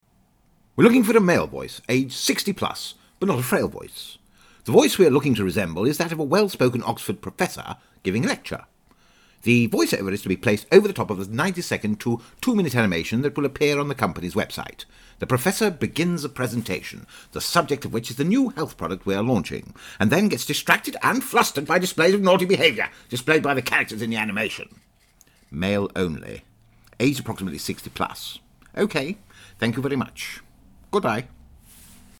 Sprecher | World Wide Voices
Deep rich warm voice with gravitas, versatile voice actor, characterisations, authoritative, commanding,
Sprechprobe: Sonstiges (Muttersprache):